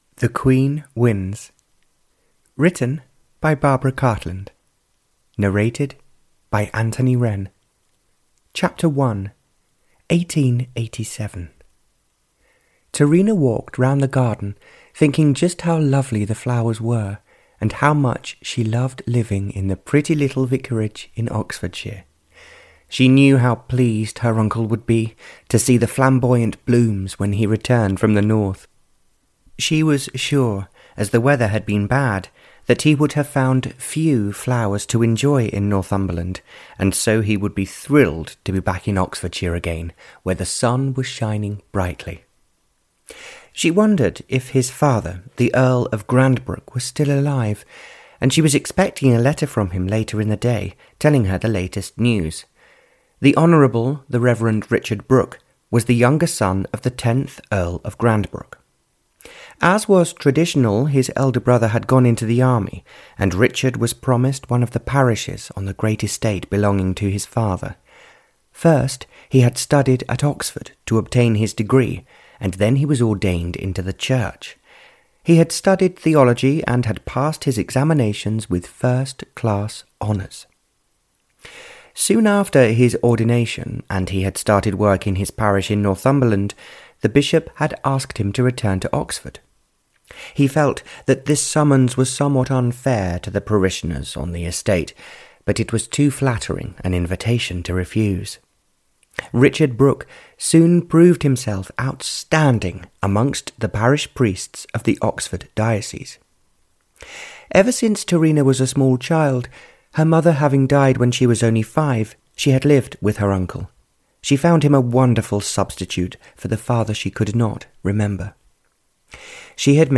Audiobook The Queen Wins (Barbara Cartland's Pink Collection 94), written by Barbara Cartland.
Ukázka z knihy